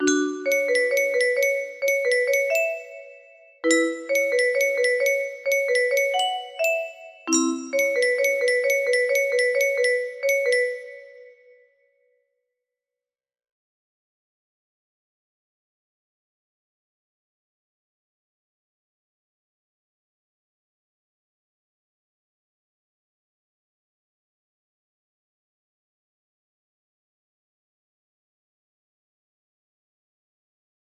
25-28 music box melody